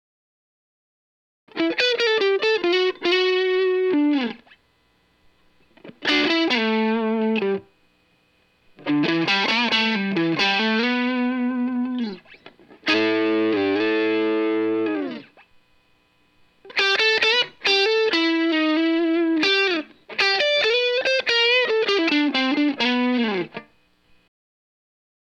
SM57 sounding too harsh.
No EQ, effects, or processing in DAW. Raw tracks.
Only the mic was moved. Each clip includes some guitar vol rolling and poor playing. I start clean and roll the vol up with big chords.